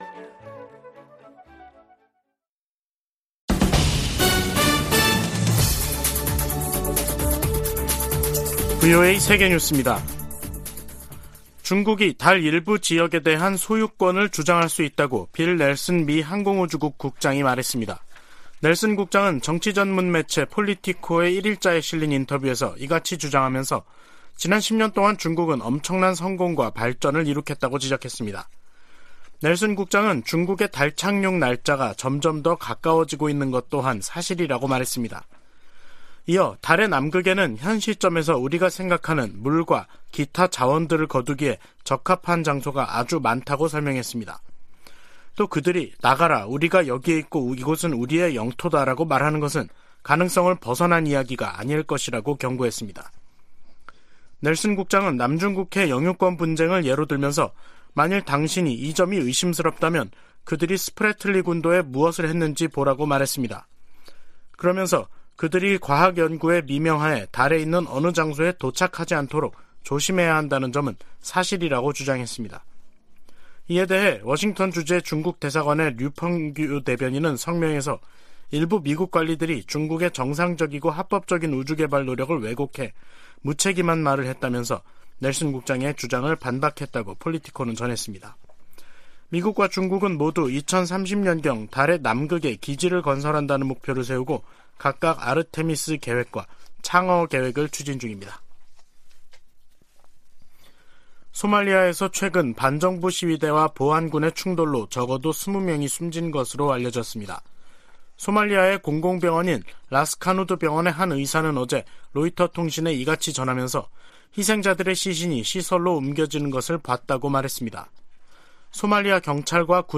VOA 한국어 간판 뉴스 프로그램 '뉴스 투데이', 2023년 1월 2일 3부 방송입니다. 미국 국무부가 이틀 연속 탄도미사일을 발사한 북한을 비판했습니다.